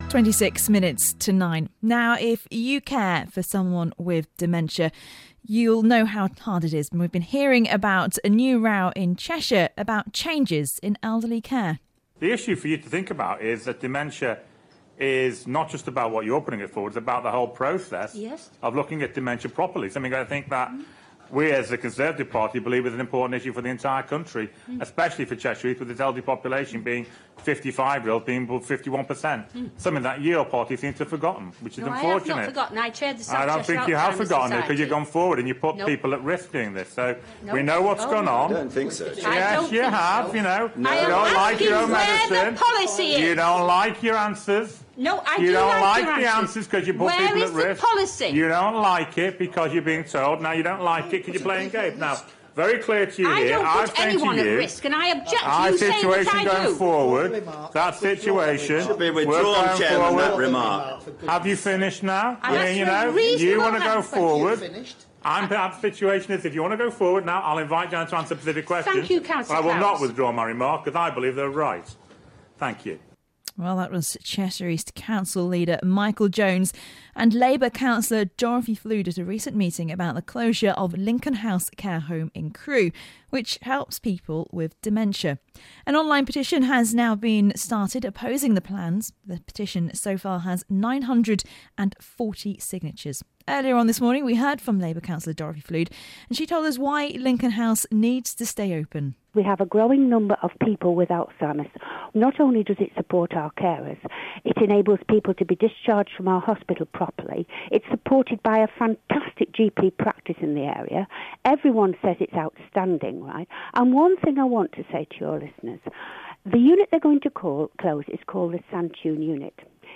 Cheshire East Council leader Michael Jones on BBC Radio Stoke about his council's plan to take dementia respite care out of Lincoln House in Crewe. Meeting audio from Cheshire East Council.